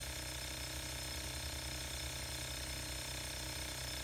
今回は防音設備が整った音楽スタジオで、アロマディフューザー３機種と加湿器の音量を実際に計測・録音しました。
他社製品50.9db38db
※iphone15で各機器から50cm離して録音しました。
• 倍率： 50.9 dB は 37.2 dB より、約2.6倍もうるさく感じます。